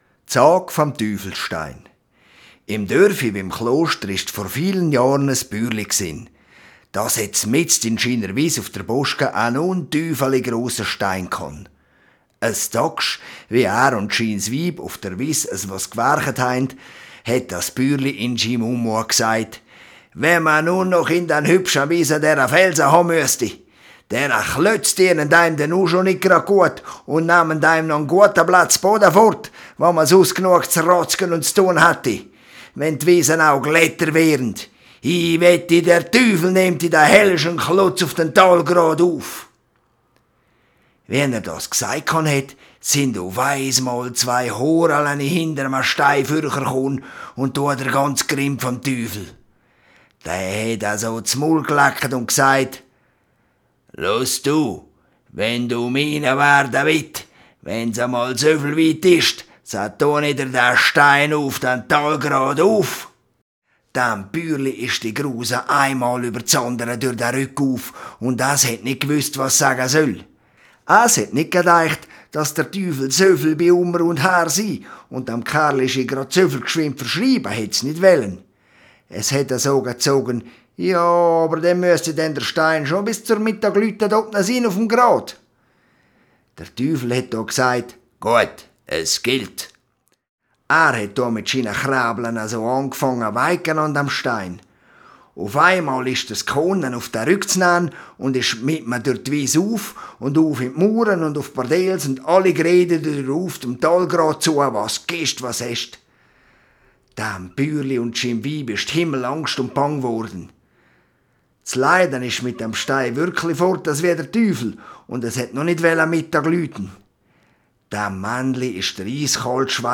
Im Heubett kann man sich einnisten und unter den lampenförmigen Lautsprechern eine Reise durch die Sagen der Region machen.